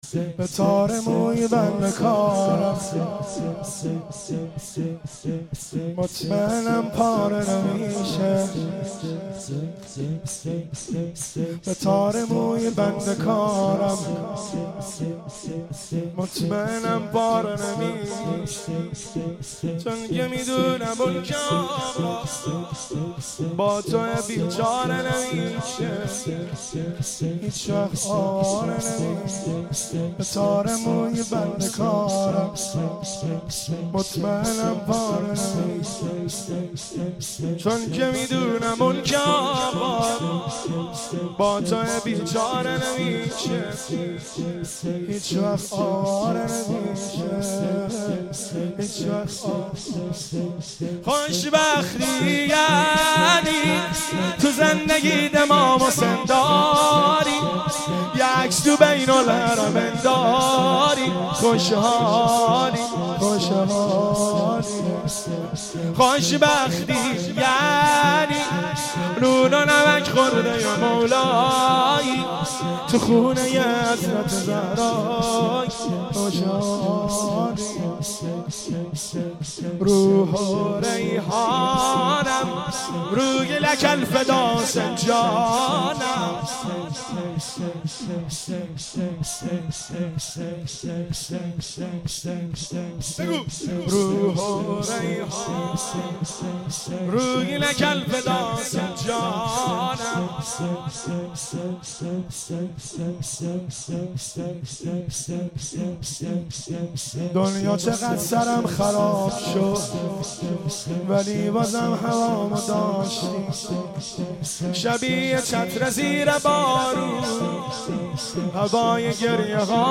مناسبت : وفات حضرت زینب سلام‌الله‌علیها
قالب : شور